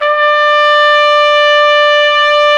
Index of /90_sSampleCDs/Roland L-CDX-03 Disk 2/BRS_Trumpet 1-4/BRS_Tp 3 Ambient